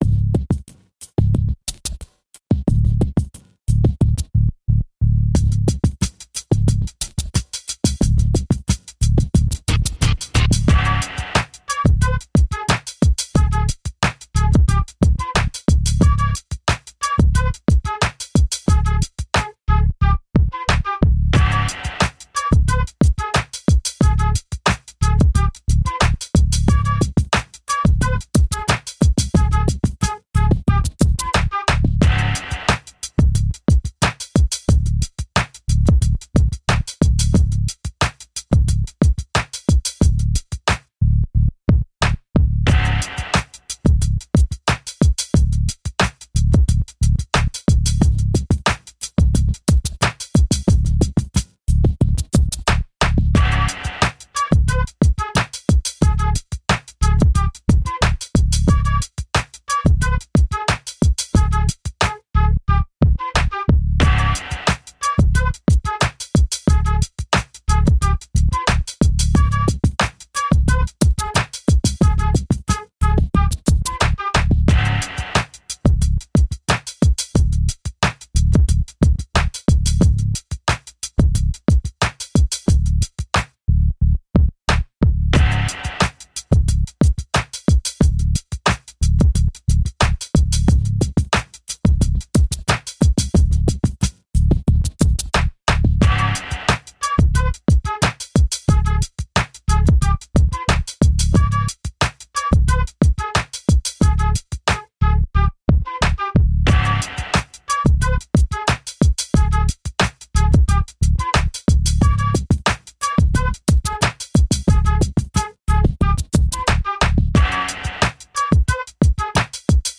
Dub, reggae, electronica, downtempo, chill out, trip hop.